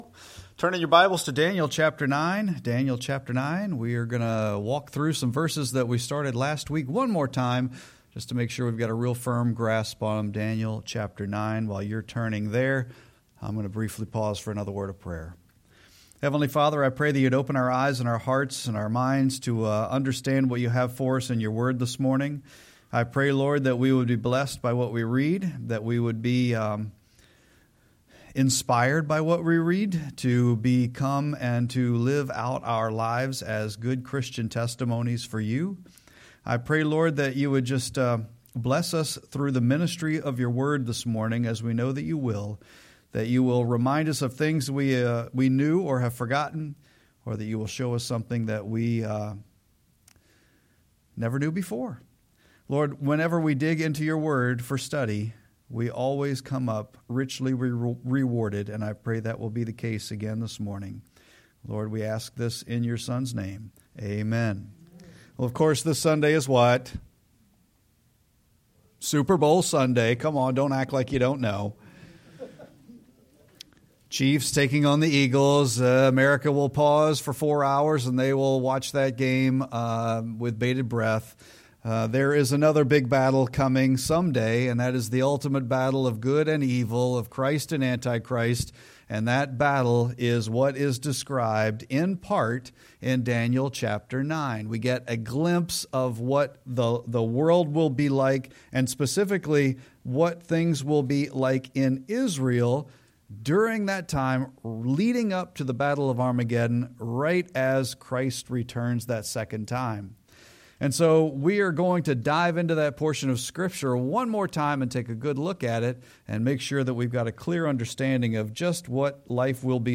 Sermon-2-9-25.mp3